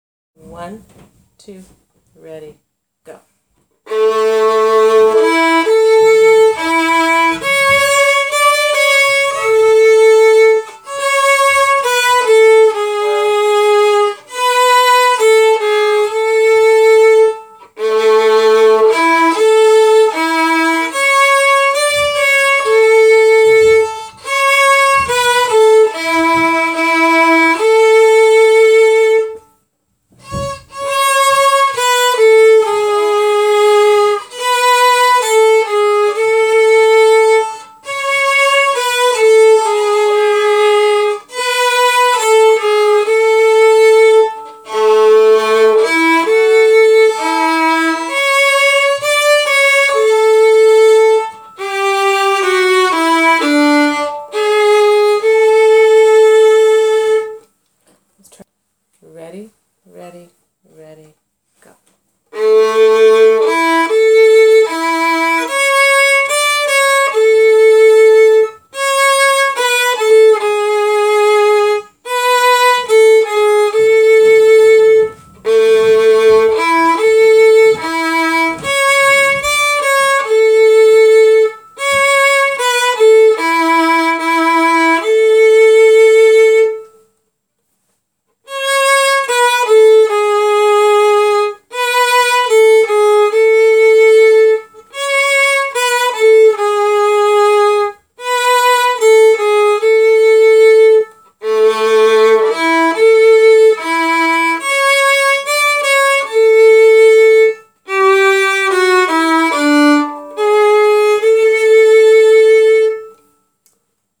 Long Long Ago duet